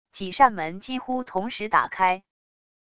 几扇门几乎同时打开wav音频